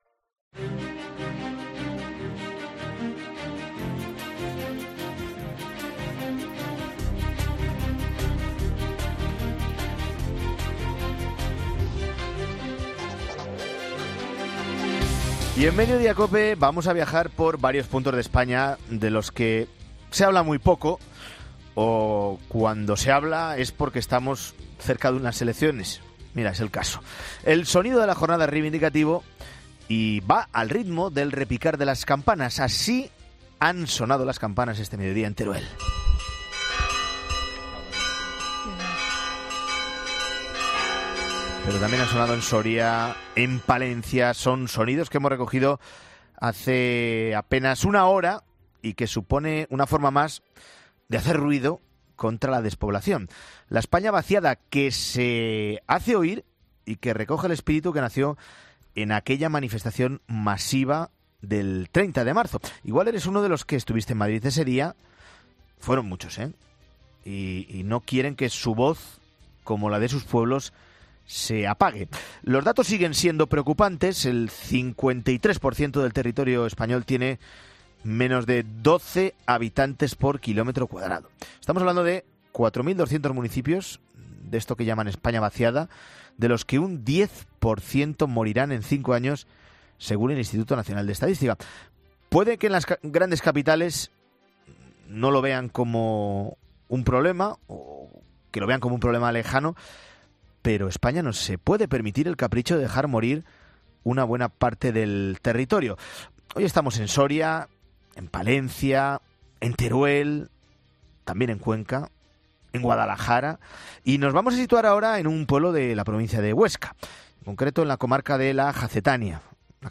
Esta mañana han sonado las campanas en diversos lugares de España para visibilizar la despoblación
El sonido de la jornada es reivindicativo y va al ritmo del repicar de las campanas.